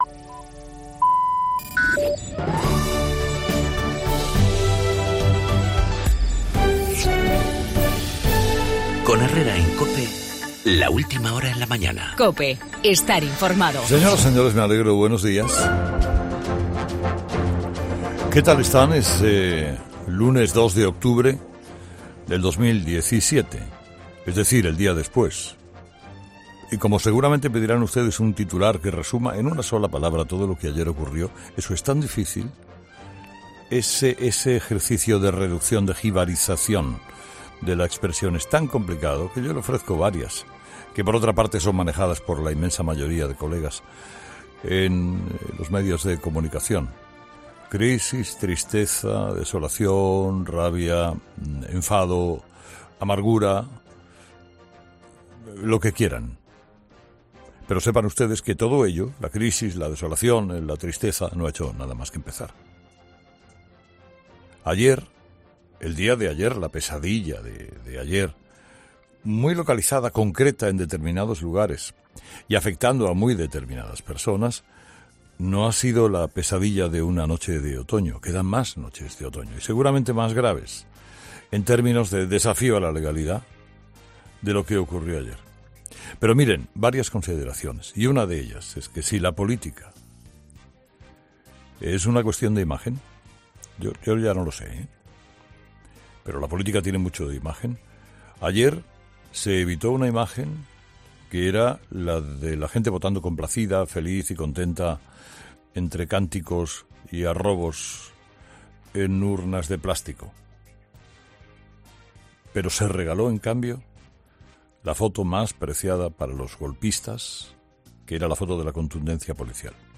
Las cargas que realizaron Policía y Guardia Civil ante la pasividad de los Mossos en el referéndum ilegal, en el editorial de Carlos Herrera